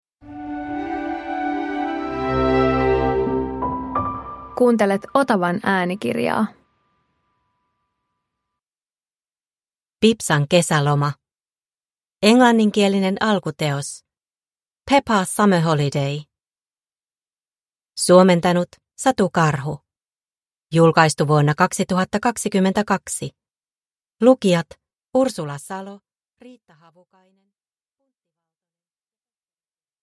Pipsan kesäloma – Ljudbok – Laddas ner